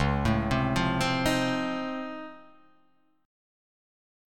Db+M9 chord